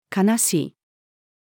悲しい-female.mp3